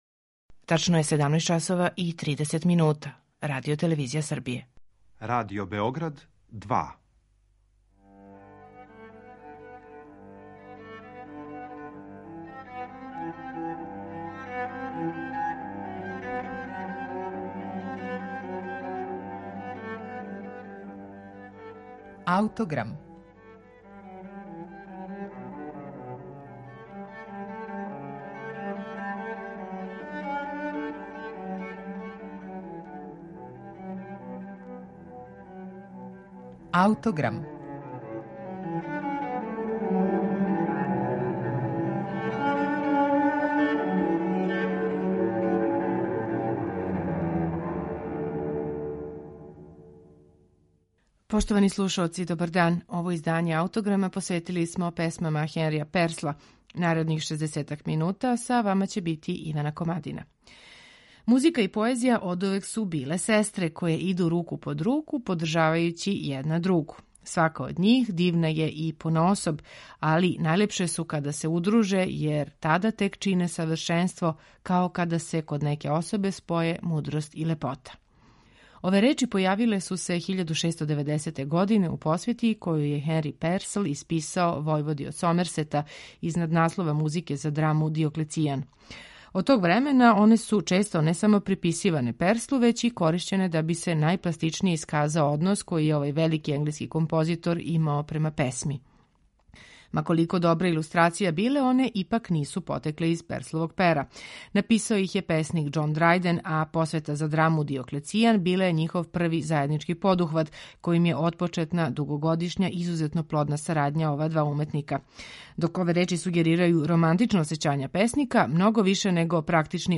сопран
оргуље и спинет
лаута
виола да гамба
виолина.